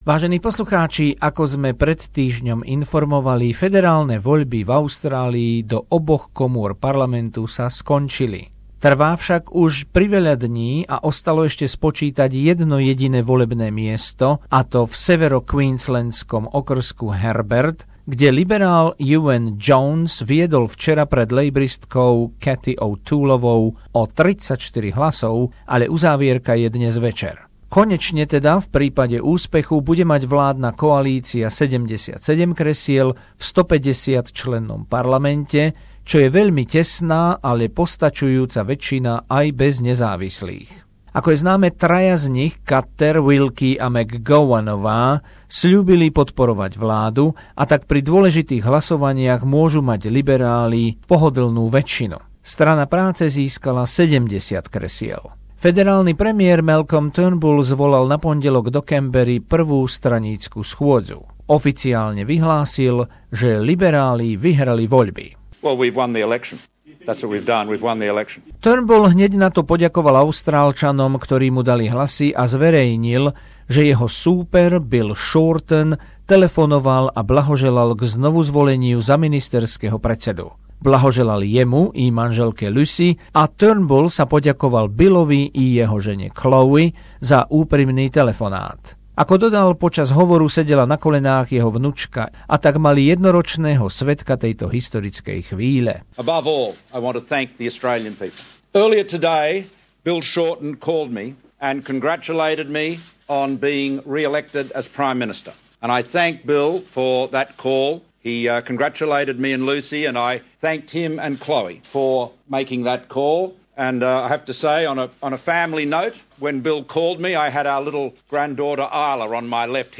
Volebné spravodajstvo z dielne SBS o víťazstve liberálov do federálneho parlamentu 2016